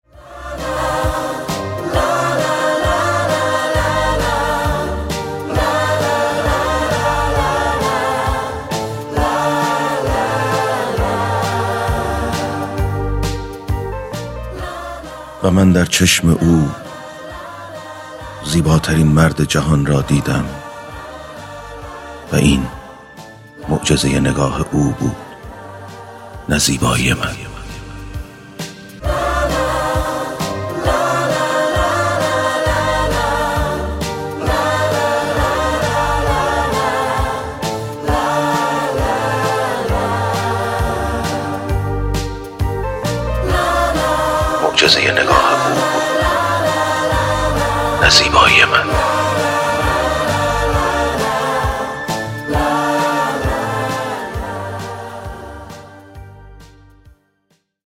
دانلود دکلمه معجزه چشم او افشین یداللهی
گوینده :   [افشین یداللهی]